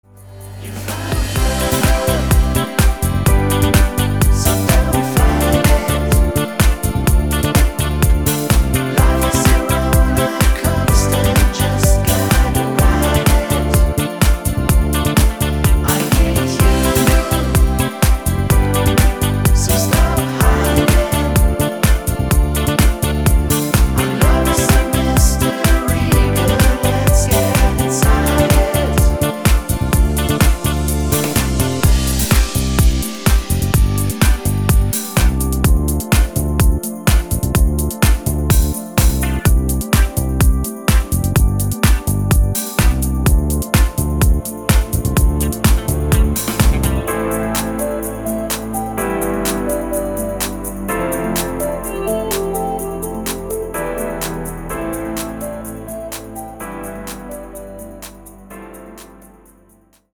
Disco Mix